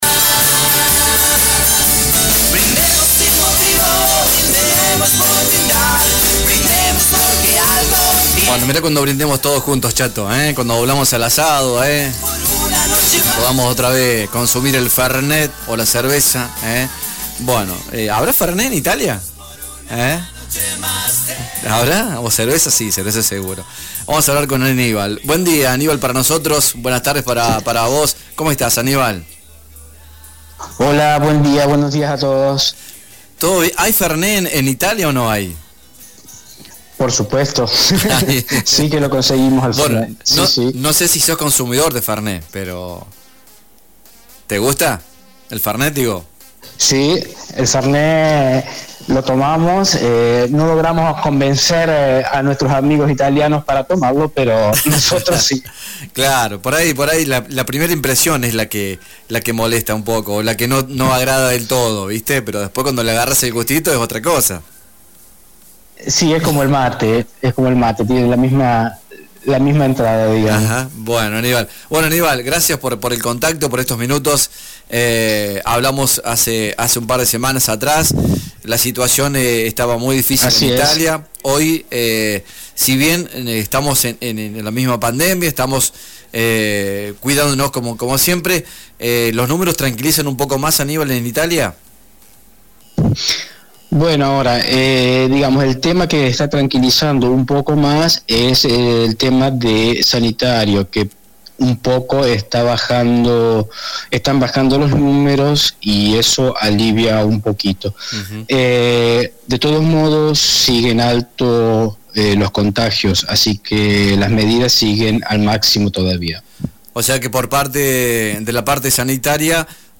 Sobre el final de la entrevista, le pidió a la gente de San Basilio que siga todas las recomendaciones y que tenga paciencia.